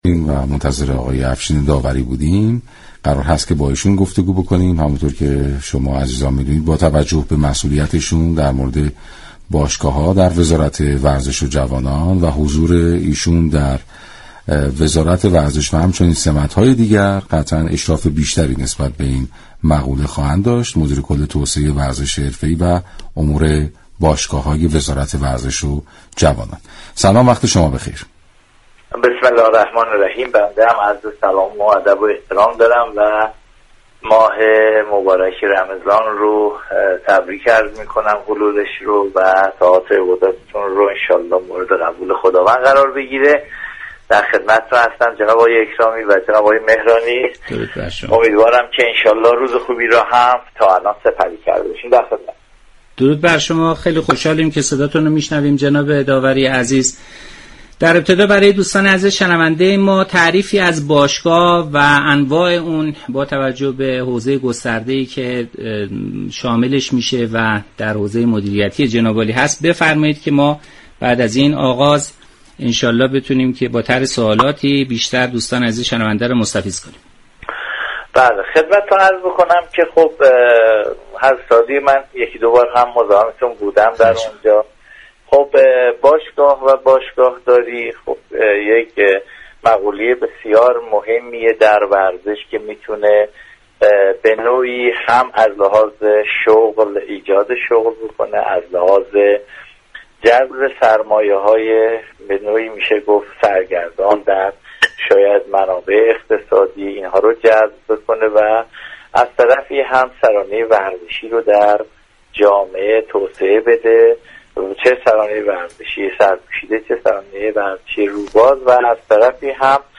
برنامه «همیشه با ورزش» رادیو ورزش یكشنبه 7 اردیبهشت با حضور افشین داوری، مدیركل توسعه ورزش حرفه ای و امور باشگاه های وزارت ورزش و جوانان به توضیح درباره نحوه خصوصی سازی باشگاه های ورزشی پرداخت.
شما می توانید از طریق فایل صوتی پیوست شنونده گفتگوی كامل افشین داوری با رادیو ورزش باشید.